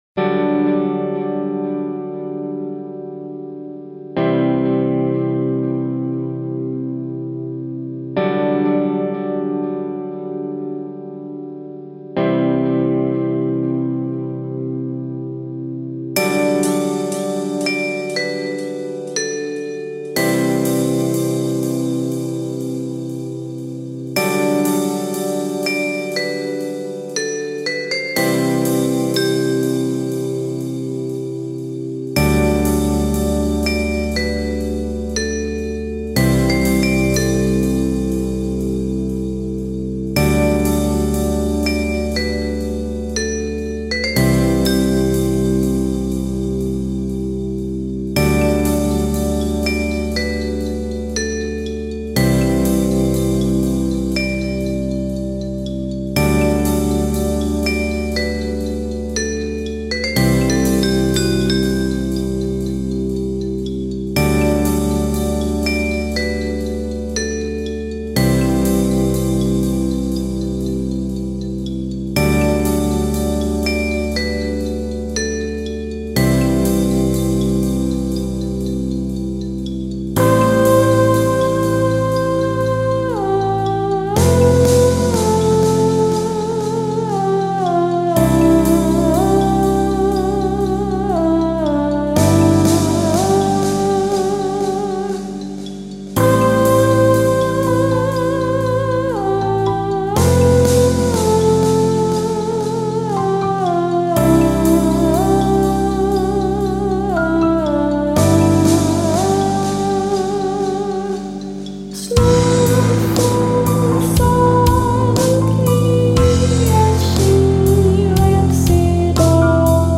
So, its both a vocaloid song and a brony song.